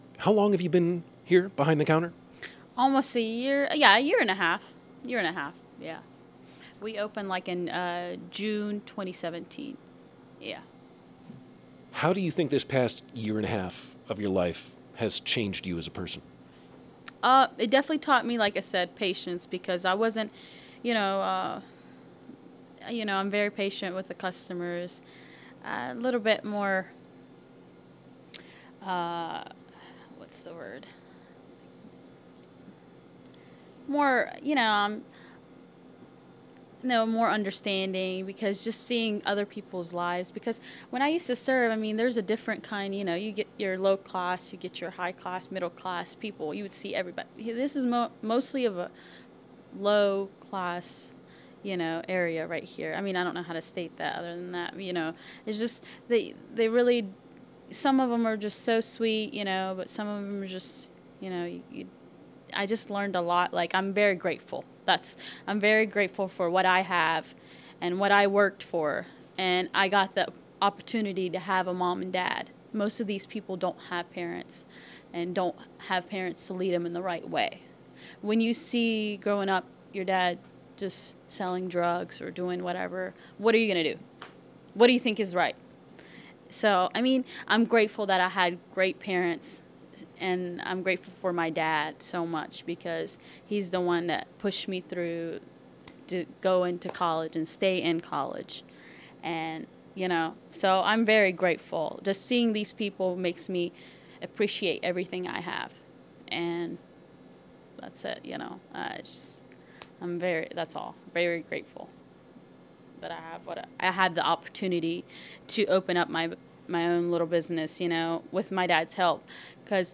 Charleston (W. Va.)